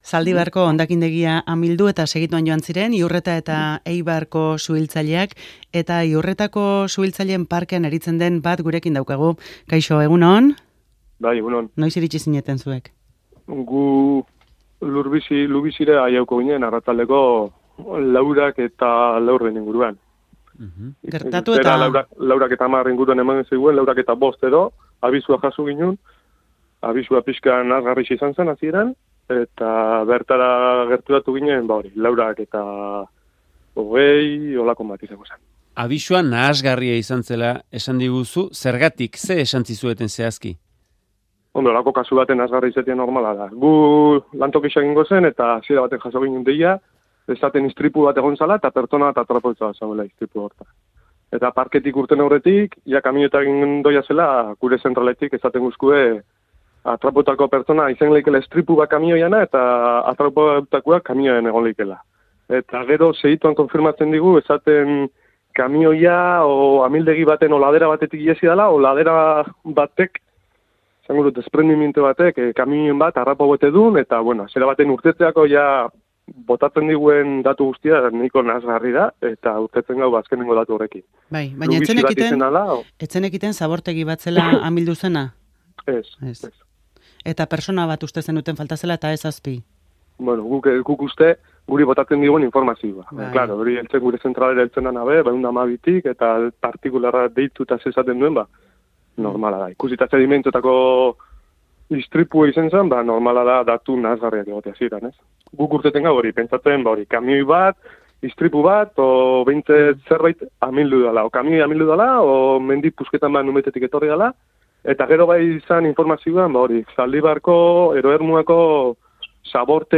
Audioa: Eitzagako zabortegiko luizian beharrezko babesik gabe hasi zituzten erreskate lanak. Iurretako parketik joandako suhiltzaile batek han bizitakoa kontatu du.